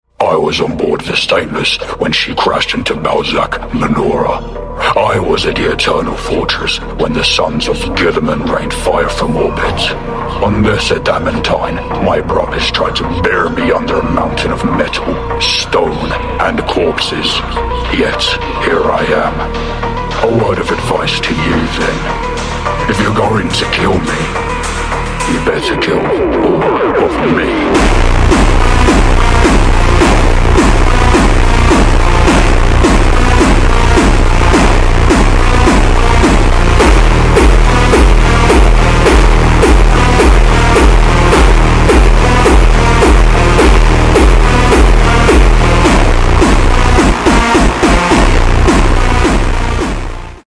First time voice acting, hope sound effects free download